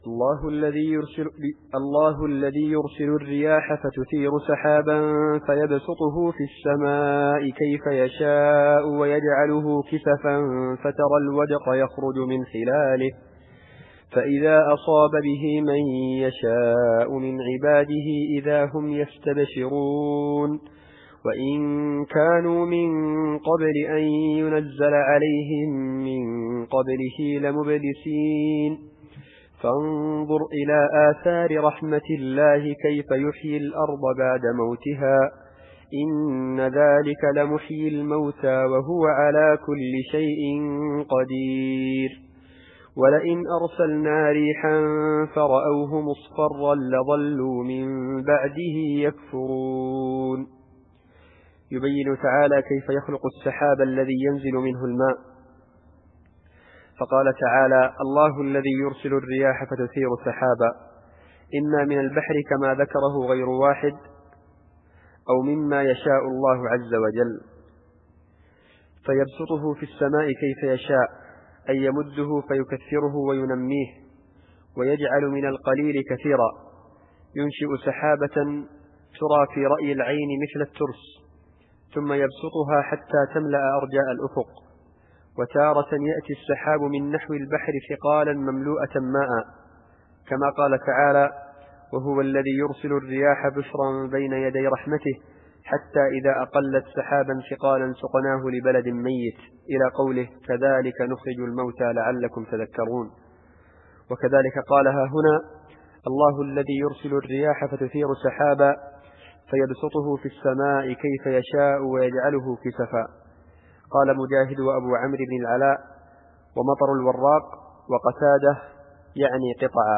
التفسير الصوتي [الروم / 48]